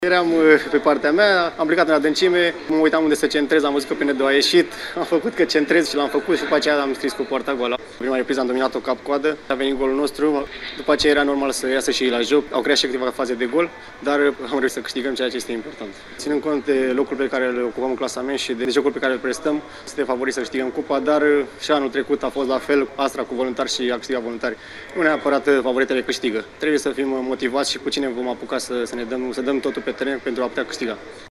Jucătorul Științei descrie golul calificării.